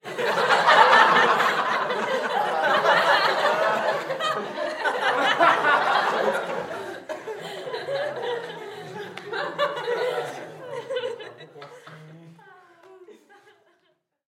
Звуки закадрового смеха
Смех зрителей в театре